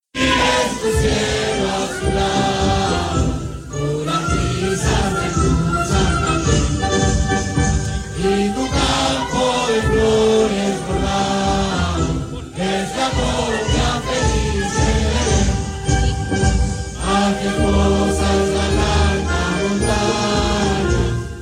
El segundo se dio en el mirador Pablo Neruda del Parque Metropolitano, donde llegaron centenares de personas para participar del cierre de campaña del Rechazo.
¿Qué protagonizó el acto? las banderas chilenas, los tradicionales C-H-I y también el canto del himno nacional, escuchemos parte de ese momento.
cuna-cierre-ambient-erechazo.mp3